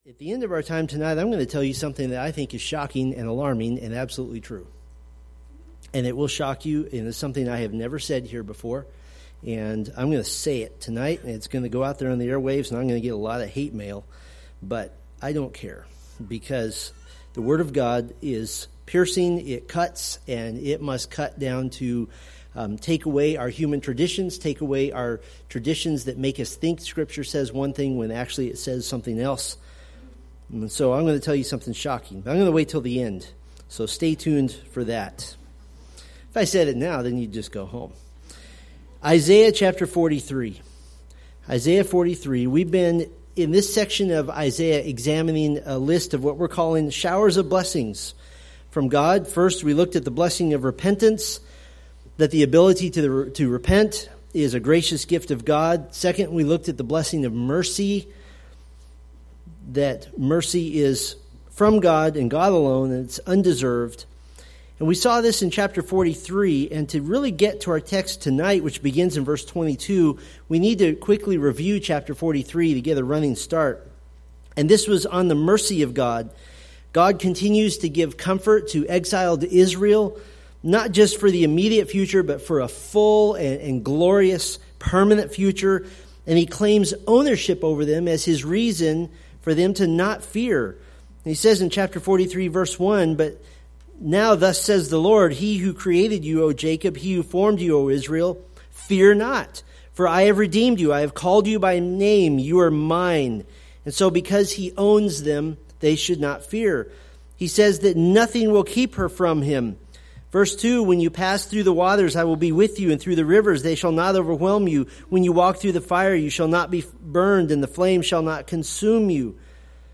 Preached March 19, 2017 from Isaiah 43:22-44:5